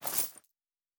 Coin and Purse 07.wav